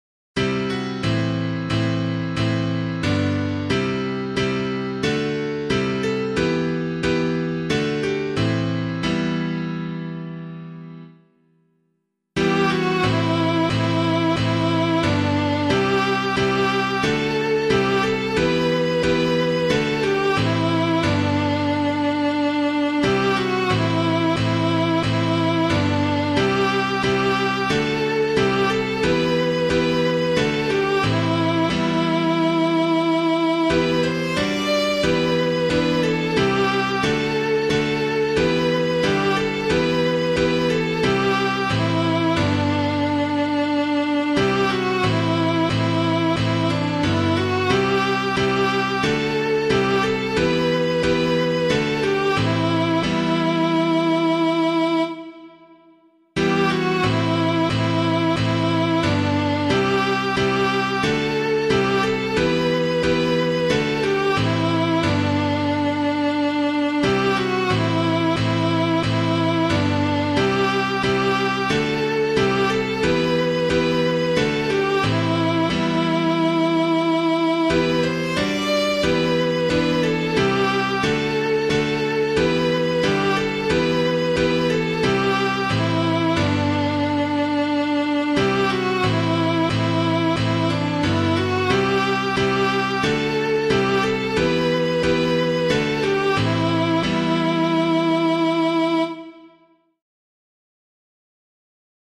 piano
All You Who Seek a Comfort Sure [Caswall - KINGSFOLD] - piano [mod].mp3